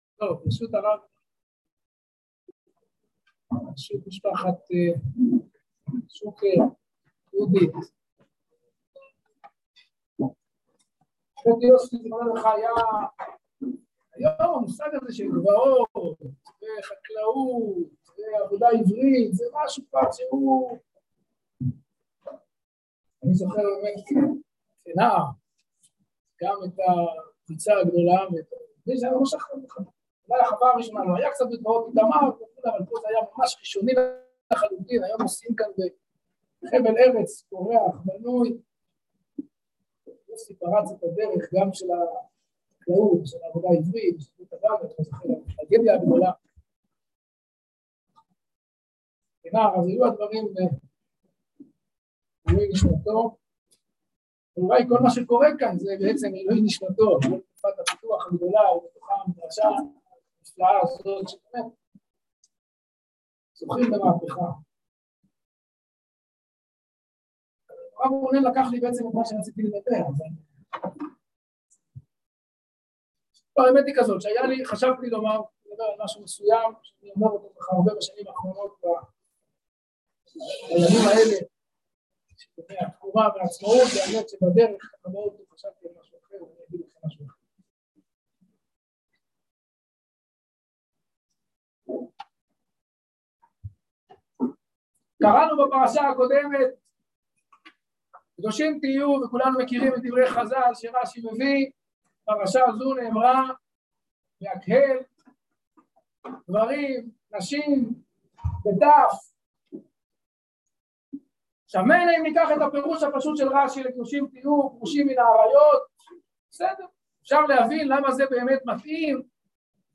שיחה חכ בצלאל סמוטריץ mp3.m4a